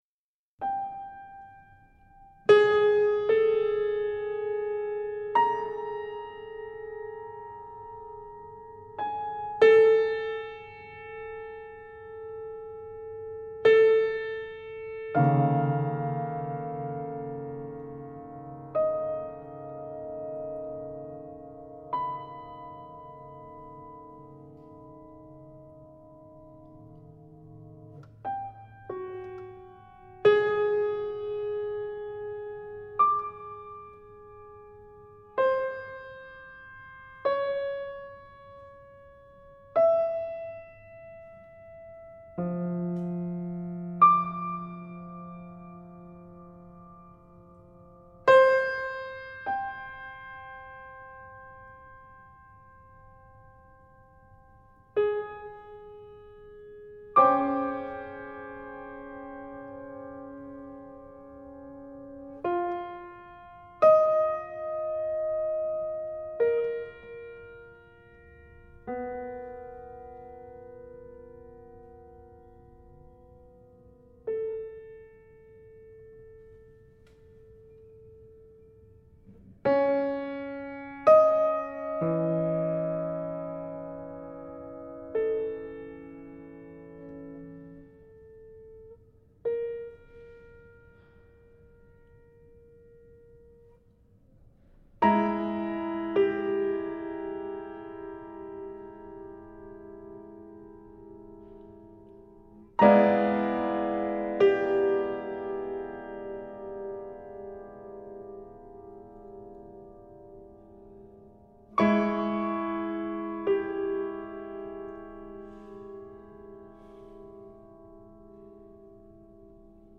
緊張と弛緩が同居した美しいピアノ作品！
叙情性を排した所から立ちのぼる、豊かな情緒が味わい深くて◎！緊張と弛緩が同居した美しいピアノ作品です！